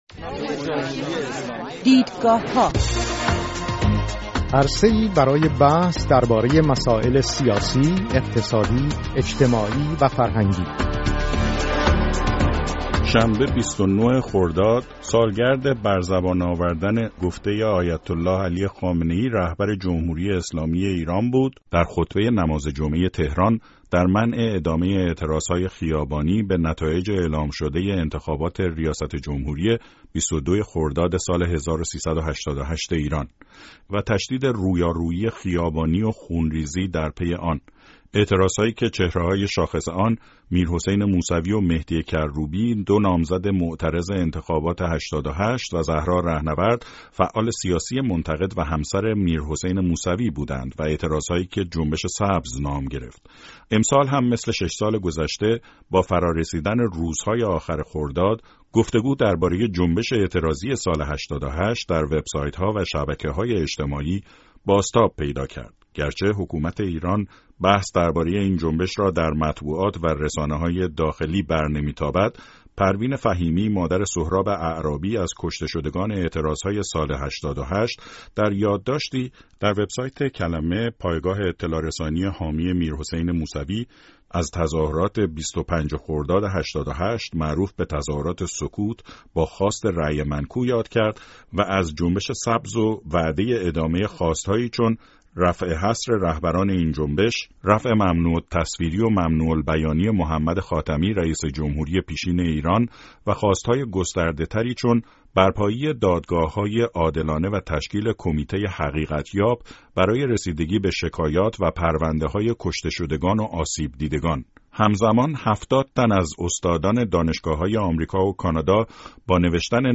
مناظره: جنبش سبز یا عبور از جنبش سبز؟
دو مهمان این هفته برنامه «دیدگاه‌ها» در این باره مناظره کرده اند.